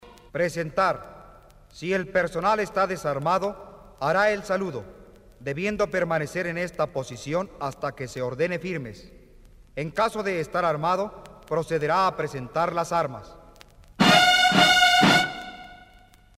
TOQUES MILITARES REGLAMENTARIOS EN MP3.
presentar.mp3